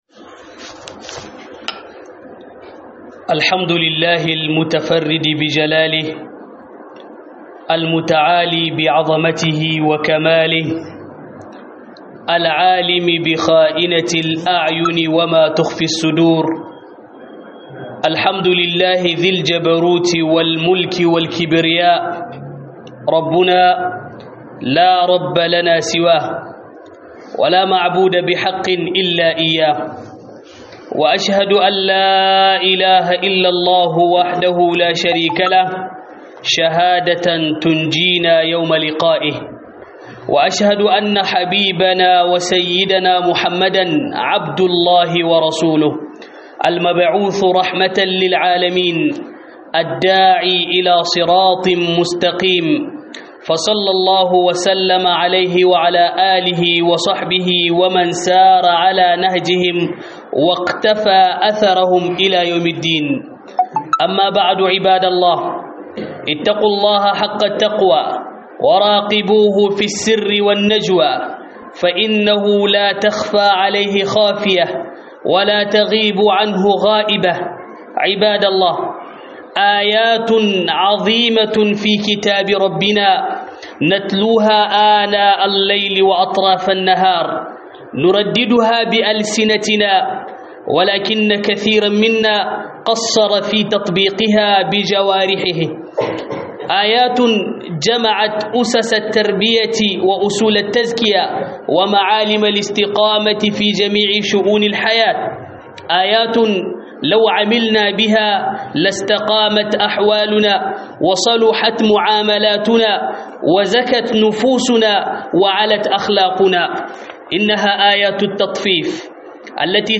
Huɗubar juma'a akan Tawaye ma'auni